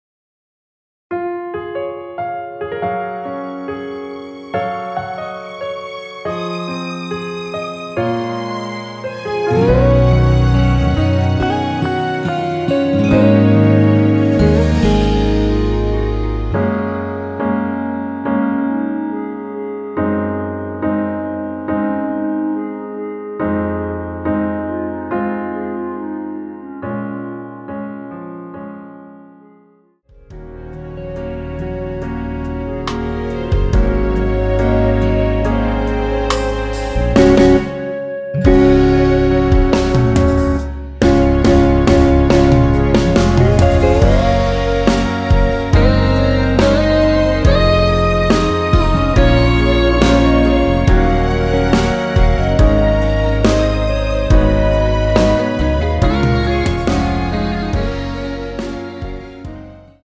원키에서(-2)내린 멜로디 포함된 (1절앞+후렴)으로 진행되는 MR입니다.
Db
멜로디 MR이라고 합니다.
앞부분30초, 뒷부분30초씩 편집해서 올려 드리고 있습니다.